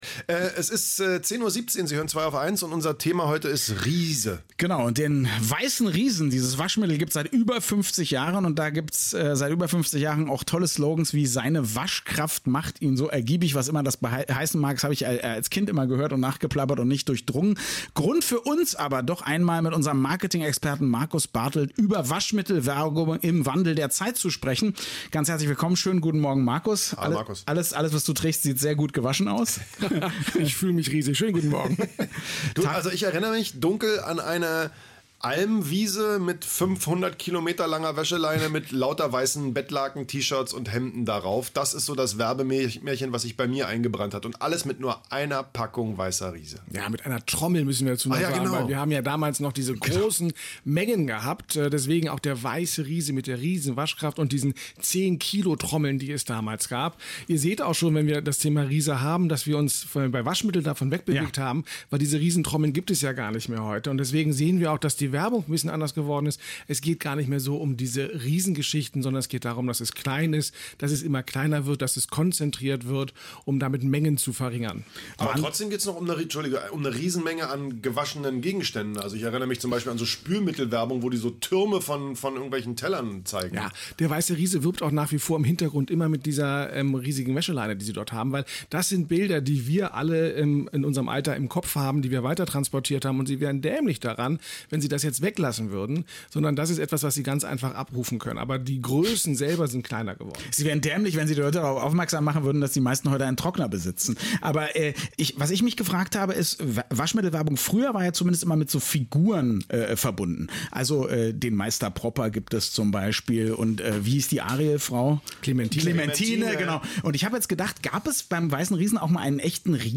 Höre ich das Thema „Riesen“ denke ich ja altersbedingt sofort an: „Wie werden wie Riesen sein, uns wird die Welt zu klein“ (der passende Song/Interpret kann gerne von euch in den Kommentaren gepostet werden), die Herren von „2aufeins“ hatten aber was ganz anderes im Hinterkopf, als sie mich ins radioeins-Studio riefen: die Waschmittel-Werbung unserer Kindheit mit der Riesenwaschkraft!